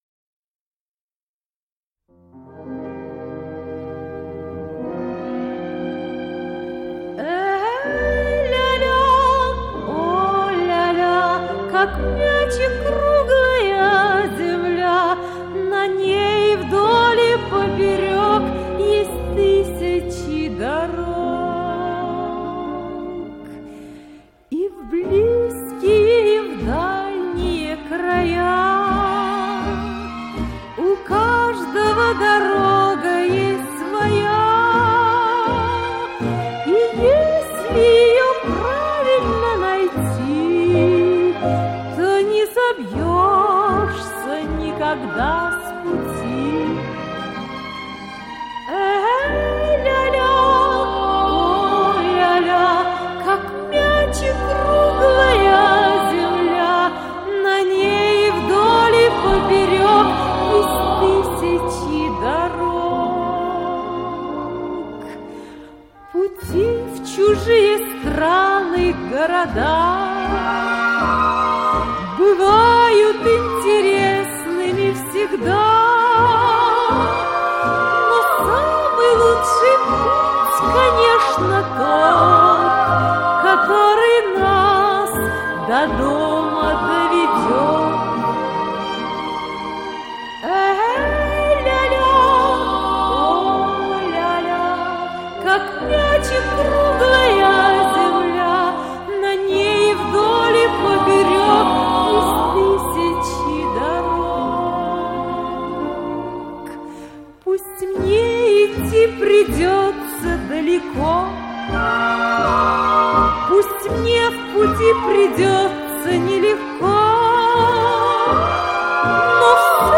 женский вокальный квартет
инструментальный ансамбль